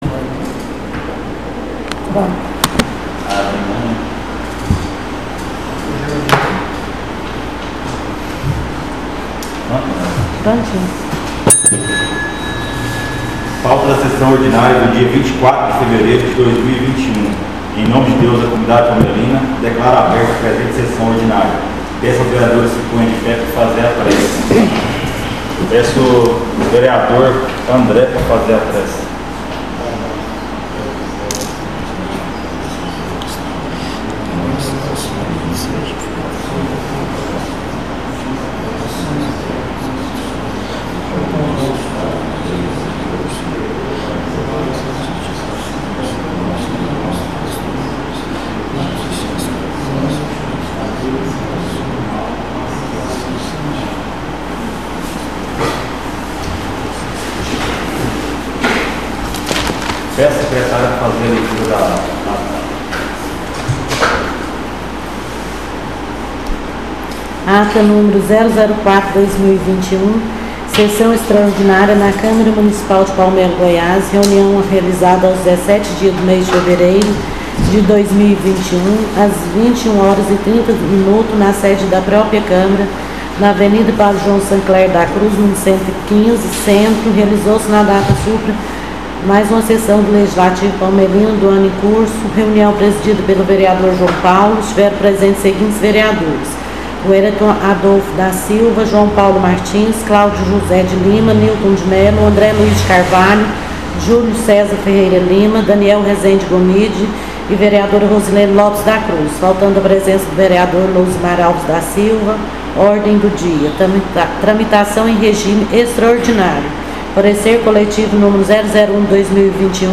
SESSÃO ORDINÁRIA DIA 24/02/2021 — Câmara Municipal de Palmelo